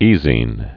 (ēzēn)